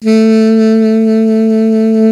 55AF-SAX03-A.wav